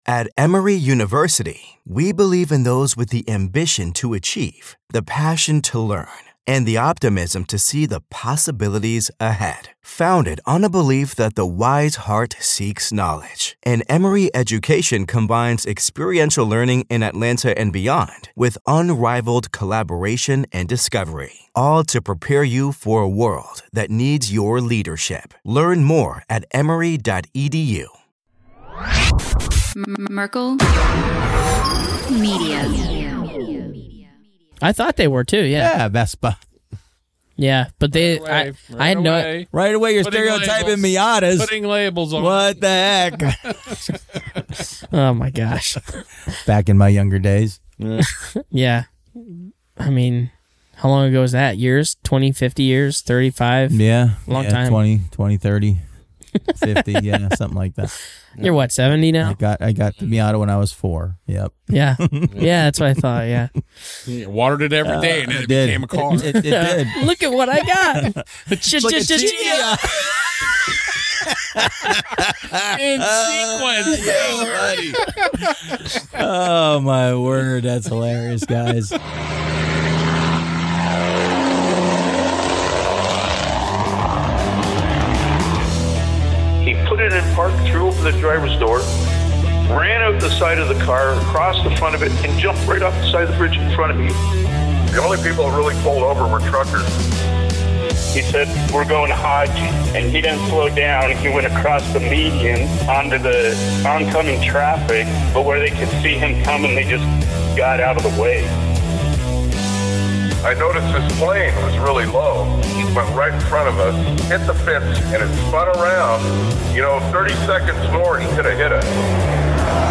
Listen in as the guys have a blast hearing a new batch of voicemails, and keep calling in to tell us all your over the road tales!